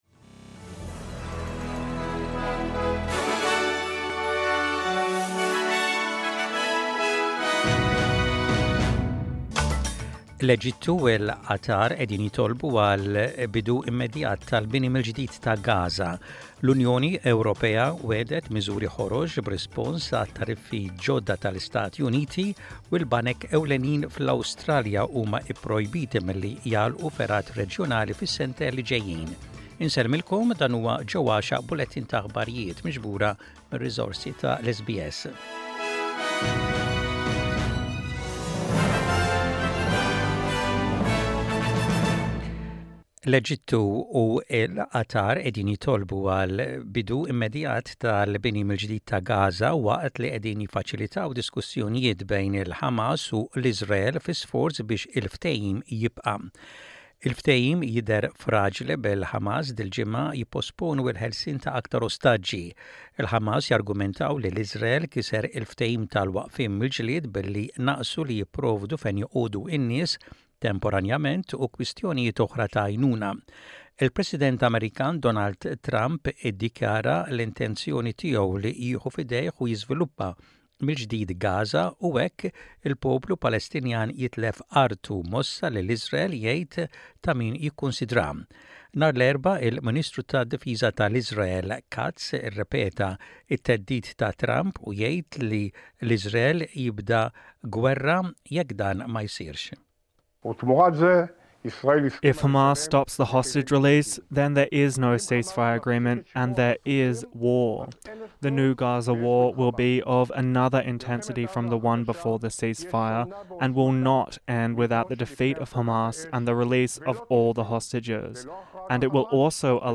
Aħbarijiet bil-Malti: 14.02.25